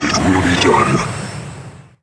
星际争霸音效-protoss-scout-pscyes03.wav